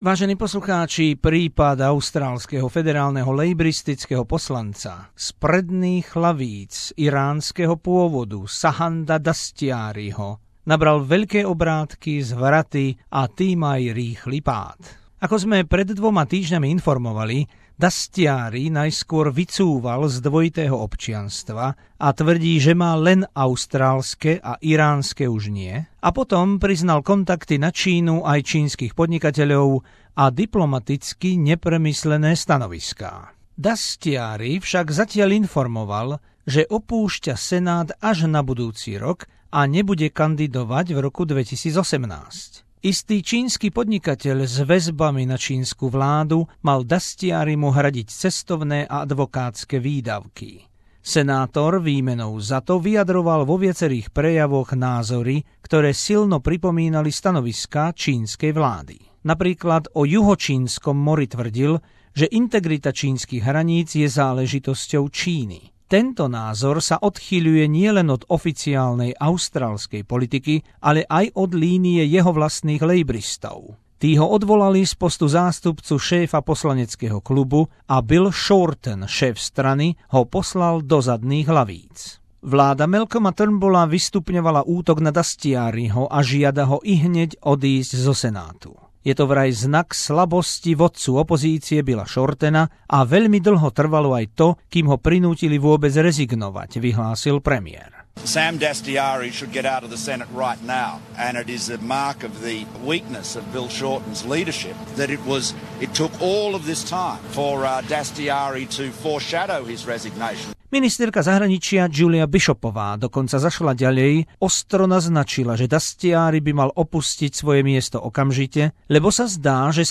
Zo spravodajskej dielne SBS o odvolaní laboristického poslanca Sama Dastyariho z predných lavíc a výzve vlády na úplné a okamžité odstúpenie zo Senátu po tom, čo Dastyari súhlasil s odchodom až na budúci rok.